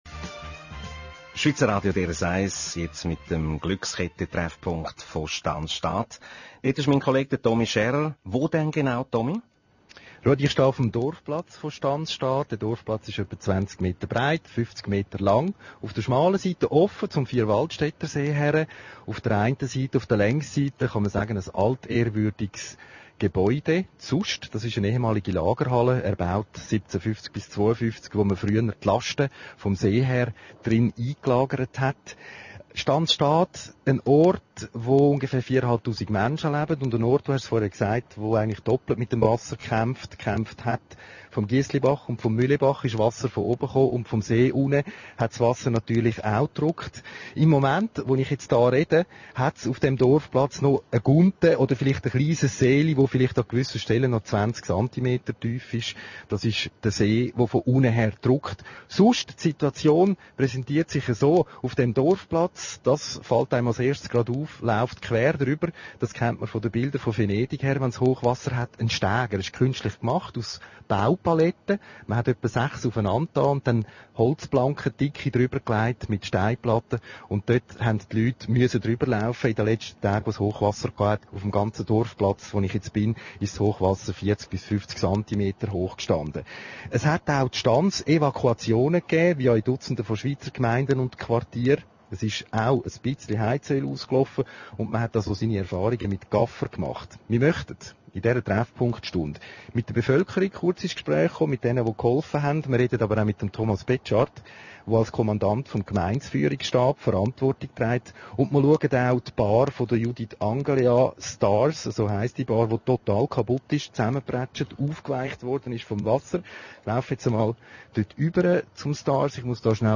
Am 31. August 2005 strahlte das Schweizer Radio DRS vom Dorfplatz Stansstad eine Treffpunktsendung aus.